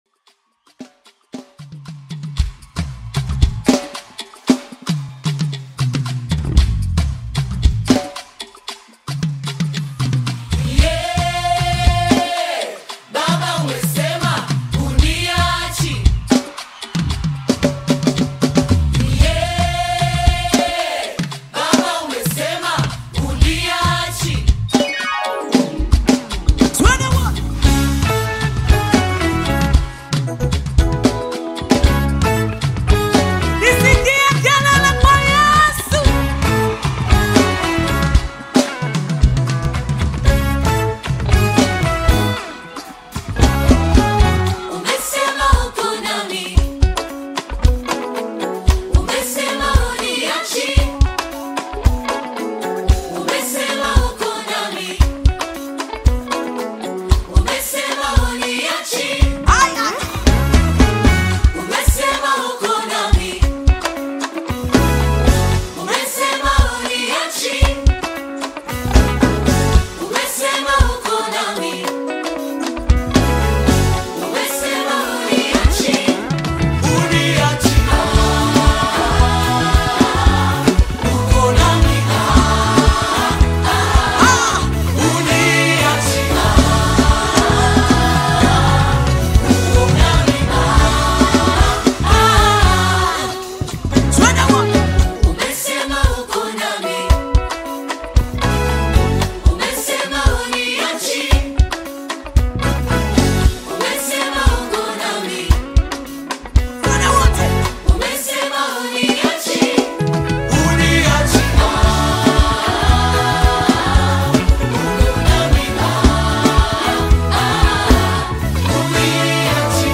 Nyimbo za Dini music
Gospel music track